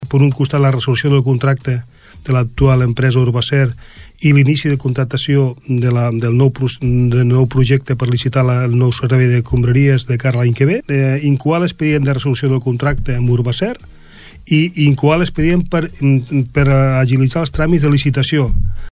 L’alcalde, Juli Fernández, explica el pronòstic que tenen la previsió que el primer tràmit es pugui fer al ple al ple del 22 de desembre en una entrevista amb la Ràdio Palafrugell.